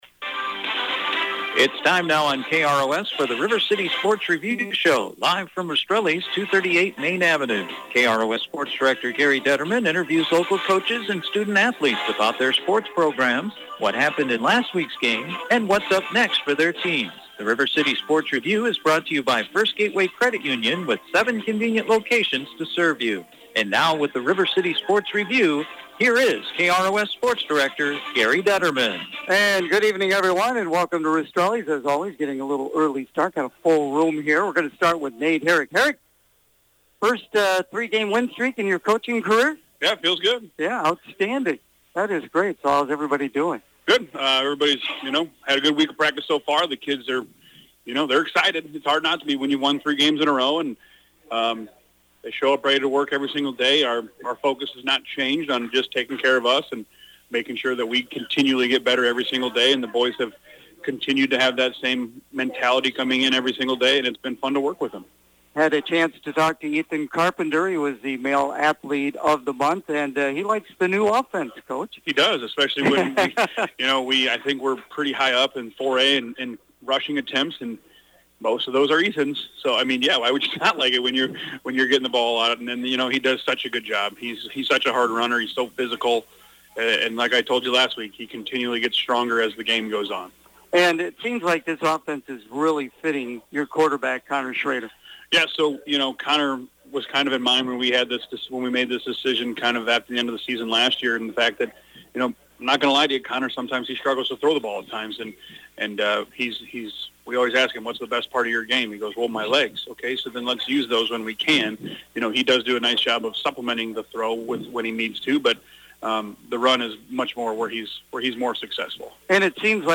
The River City Sports Review Show on Wednesday night from Rastrelli’s Restaurant
with the area coaches to preview this weekends sports action